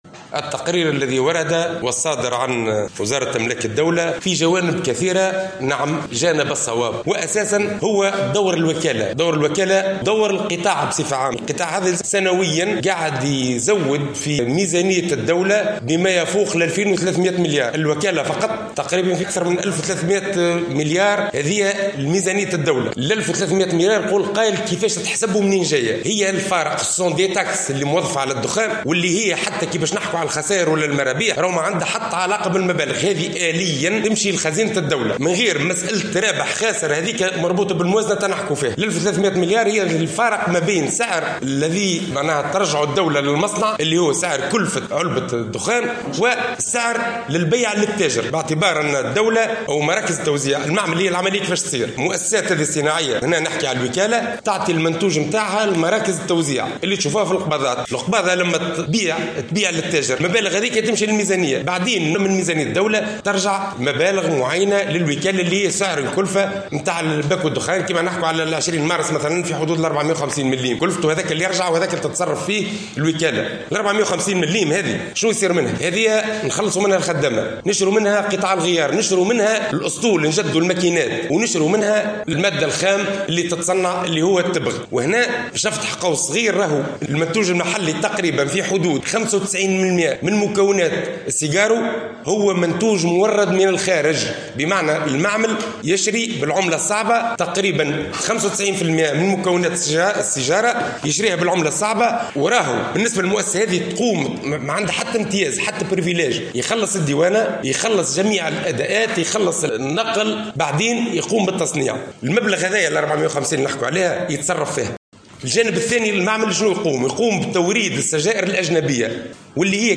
خلال ندوة صحفية عقدتها اليوم النقابة الأساسية للوكالة الوطنية للتبغ والوقيد